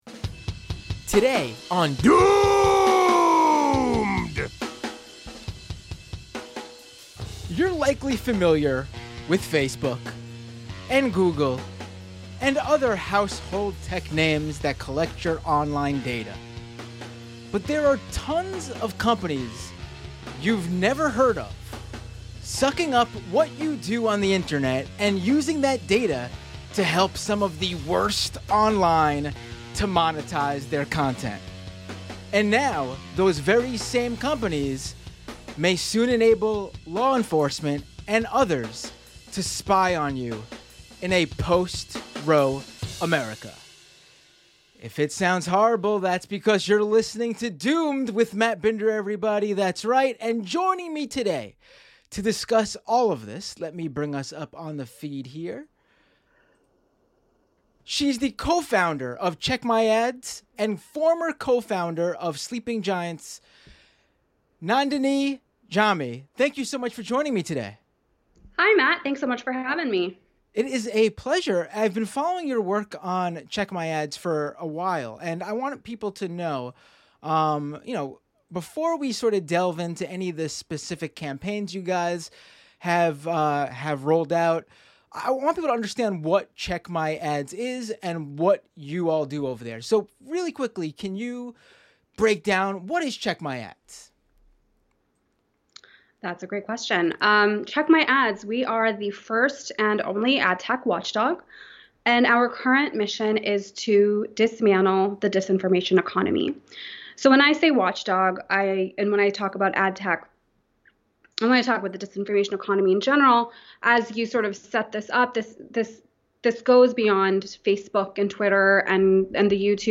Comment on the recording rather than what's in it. (Episode from the 10/28/21 livestream show.)